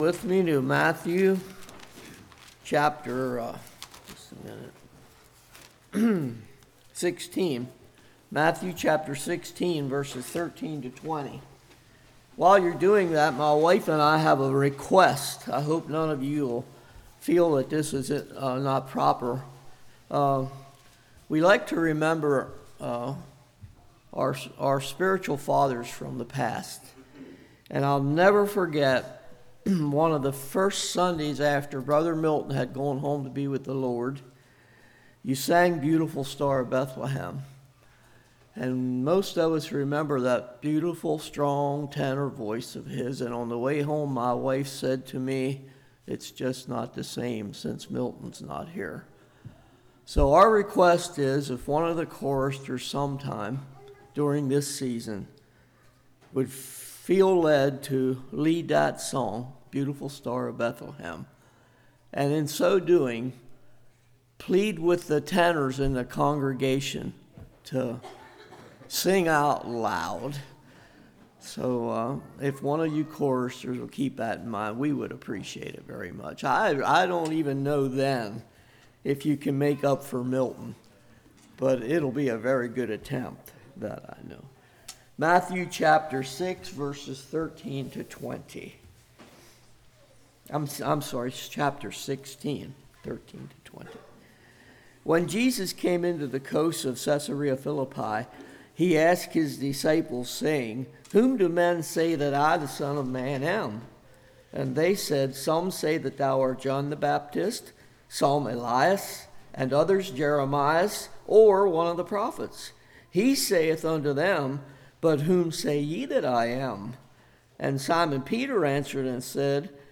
Matthew 16:13-20 Service Type: Morning The Building Is Not The Church What Is The Significance Of The Word Brethren?